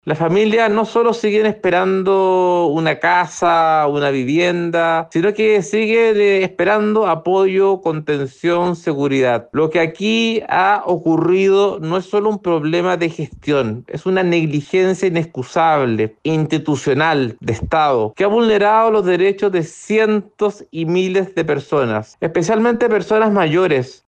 Tras la revelación de este informe, el diputado Andrés Celis anunció que solicitará una reunión con el Presidente Gabriel Boric. Además, indicó que las familias damnificadas no solo están esperando una vivienda.